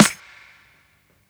AGH_SNR_2.wav